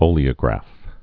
(ōlē-ə-grăf)